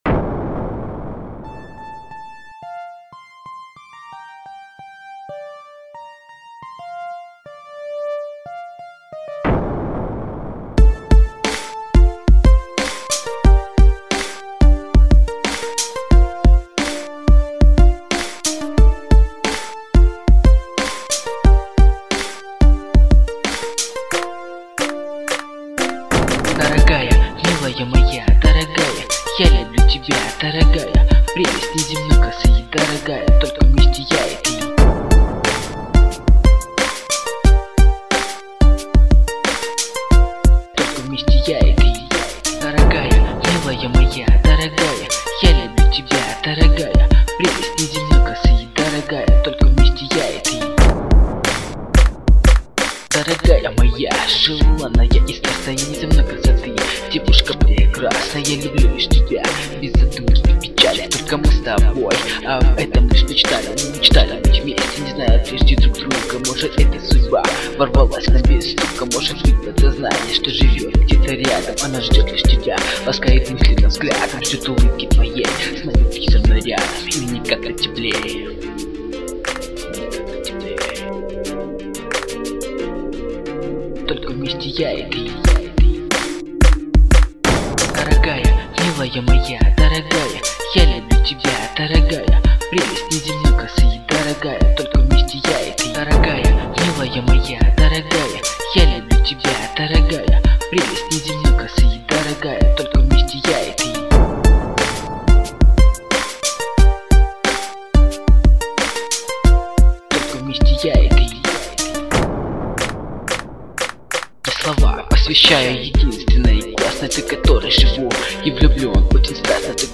Вот такой тепленький РЕП медлячёк [2009]